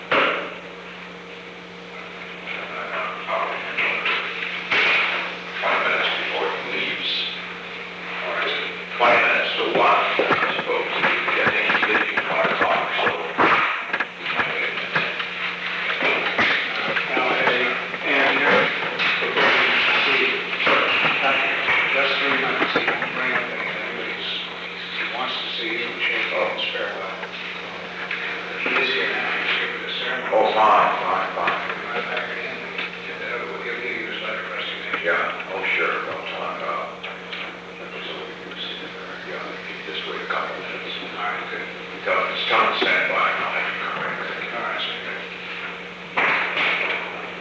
Secret White House Tapes
Conversation No. 635-13
Location: Oval Office
The President met with Alexander P. Butterfield.